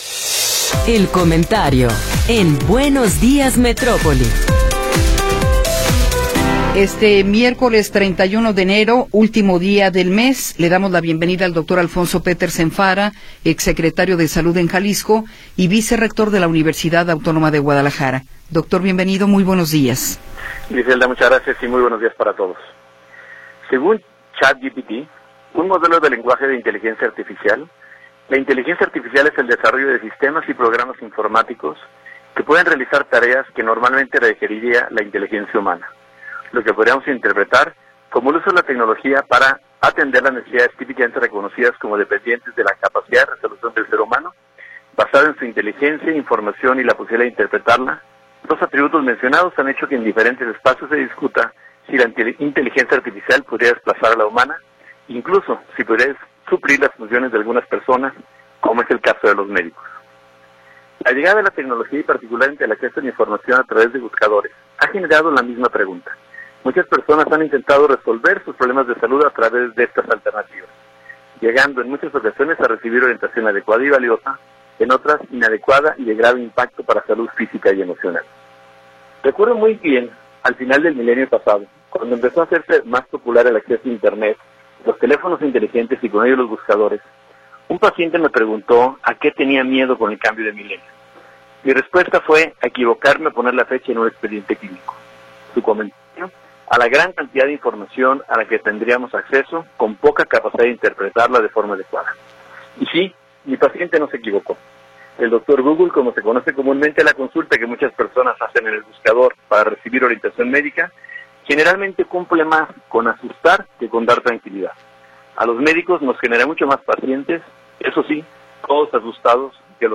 Comentario de Alfonso Petersen Farah